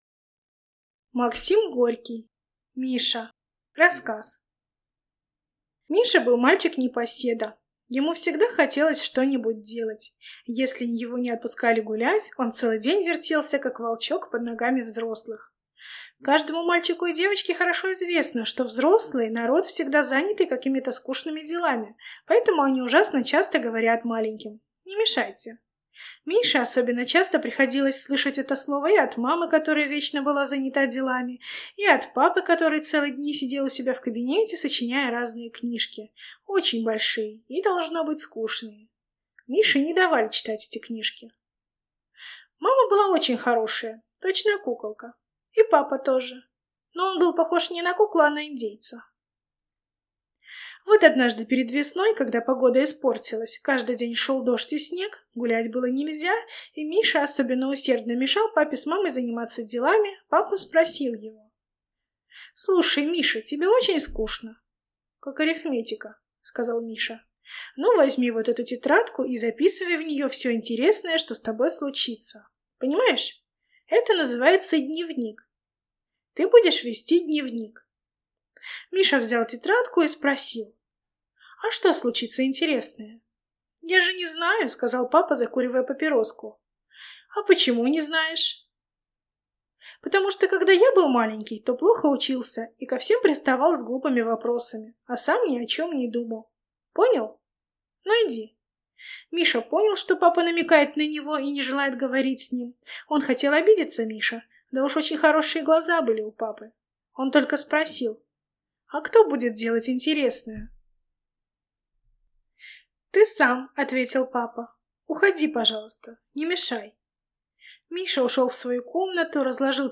Аудиокнига Миша | Библиотека аудиокниг